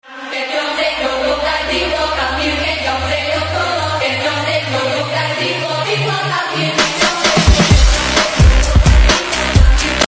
Sahroni marah dan Geram rumahnya sound effects free download